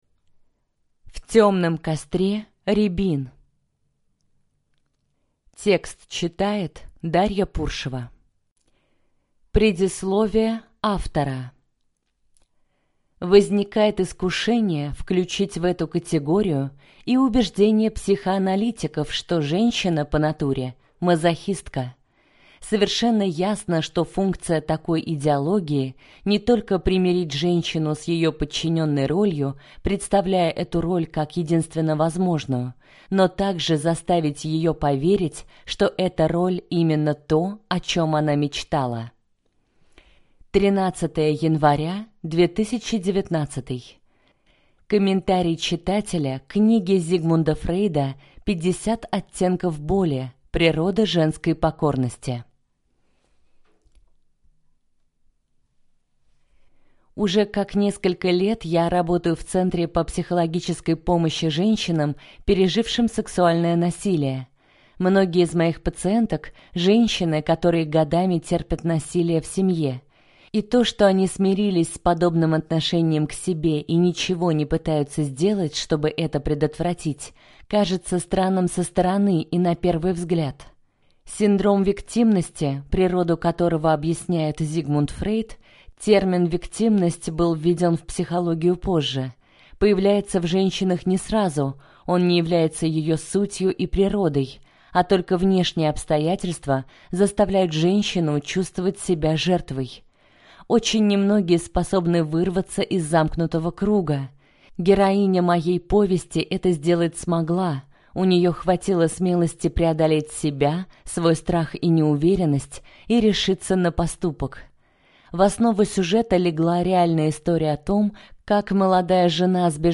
Аудиокнига В тёмном костре рябин | Библиотека аудиокниг